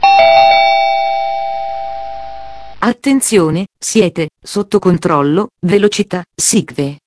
Allarmi vocali per ogni versione del TomTom
Cow = Fine SICVE                   Chuchoo = SICVE